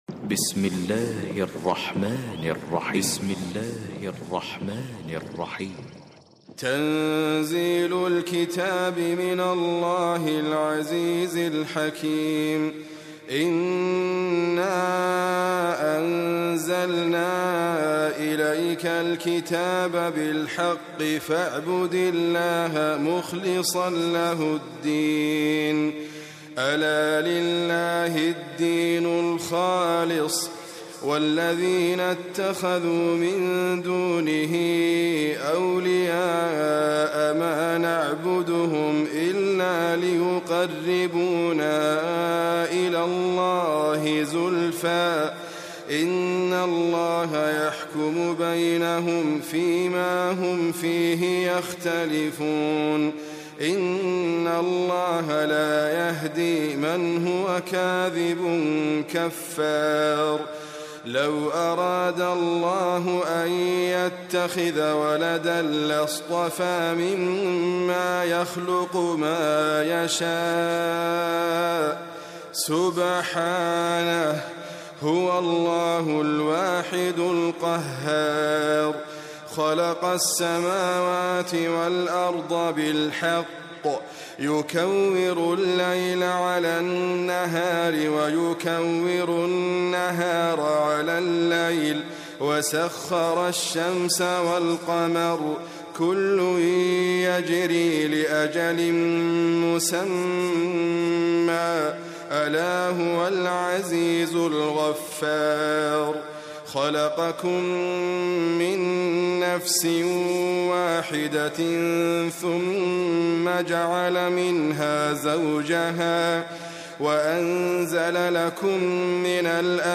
QuranicAudio is your source for high quality recitations of the Quran.